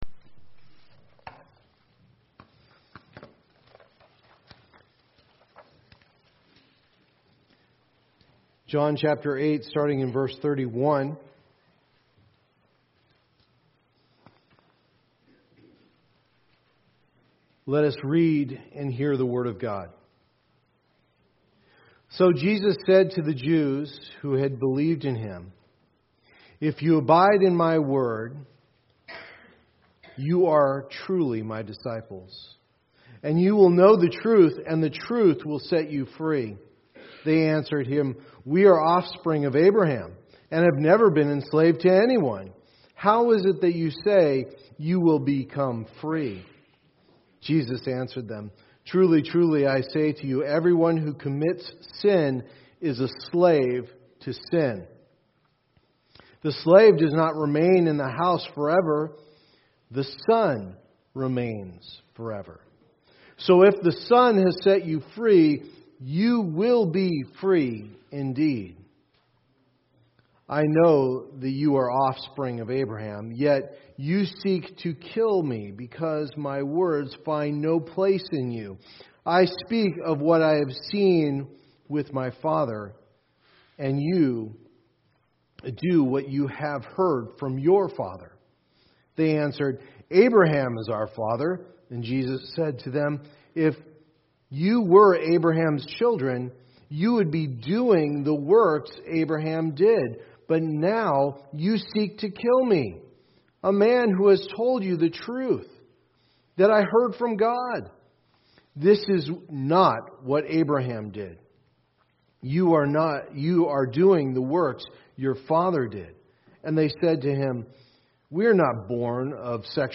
So That You May Believe Passage: John 8:31-59 Services: Sunday Morning Service Download Files Bulletin Previous Next